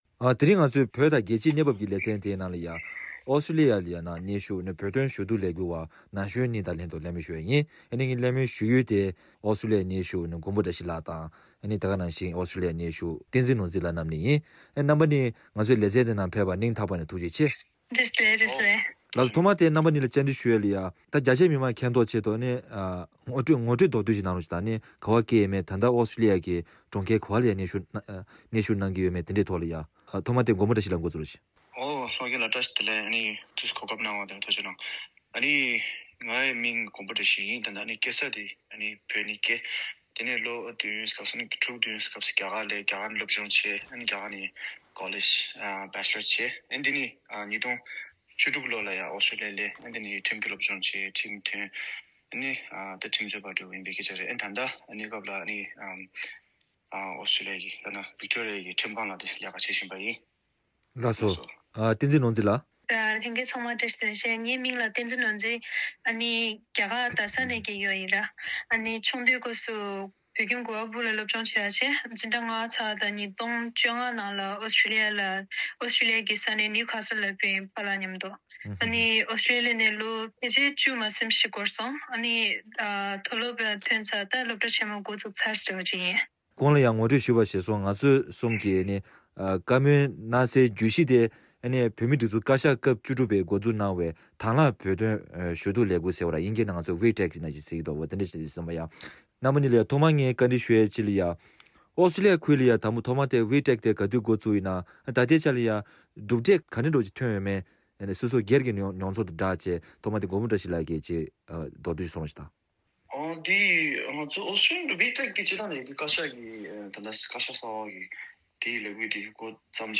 རྒྱལ་སྤྱིའི་ཐོག་མི་རབས་གསར་པ་ཚོས་བོད་དོན་ལས་འགུལ་སྤེལ་ཕྱོགས་ཞེས་པའི་བརྗོད་གཞིའི་ཐོག་བོད་རིགས་ན་གཞོན་གཉིས་དང་ལྷན་དུ་གླེང་མོལ་ཞུས་པ།